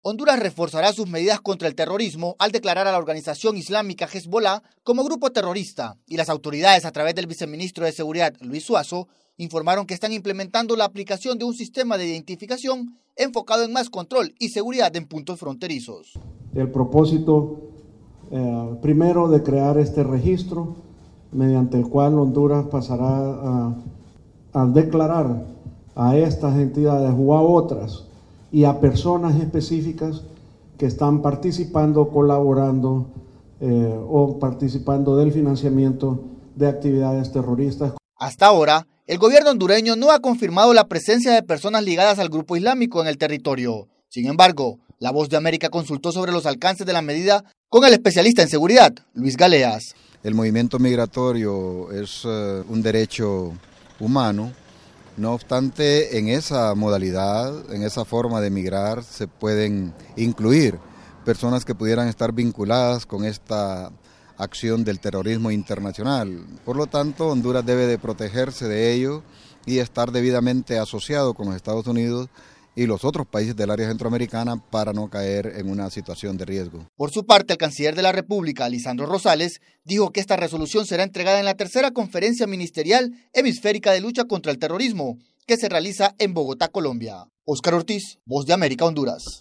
VOA: Informe de Honduras